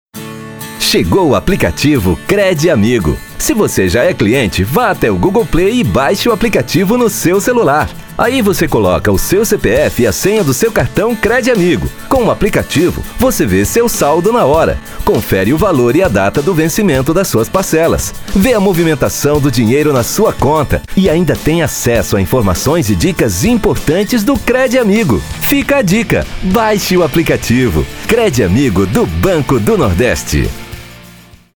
offering a confident, versatile baritone voice
Promos
Neumann tlm103, Audio Technica AT 4033, Avalon vt737SP, Audient Id14, Yamaha HS50, Mac Mini M1